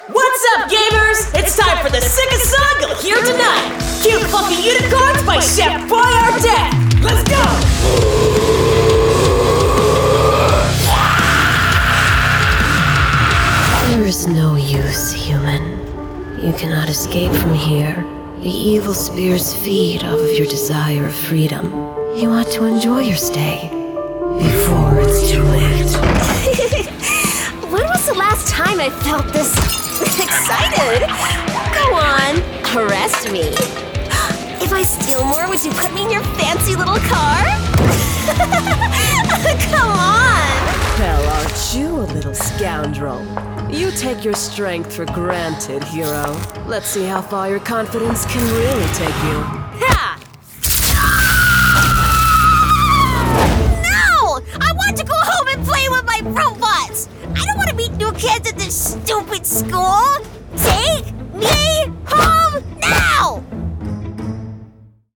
animation 🎬